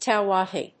アクセント・音節tów・awày 《主に米国で用いられる》